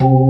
FST HMND C3.wav